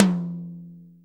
• Acoustic Tom One Shot F Key 11.wav
Royality free tom sound tuned to the F note. Loudest frequency: 1382Hz
acoustic-tom-one-shot-f-key-11-uQI.wav